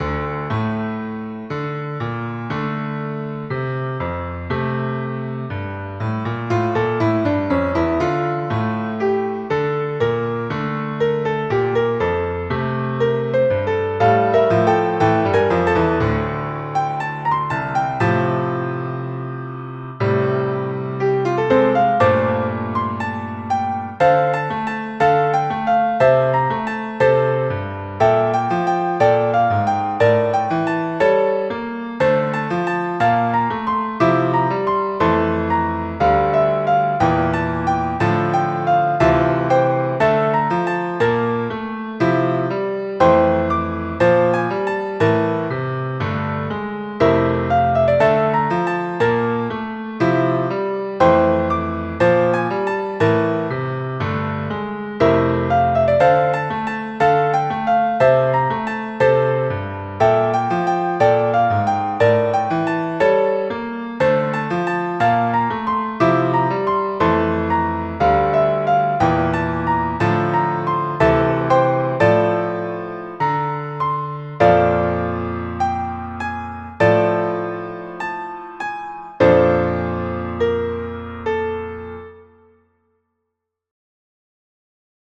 I made this in MuseScore in a couple days, it seems a lot more corporate than when I last used it.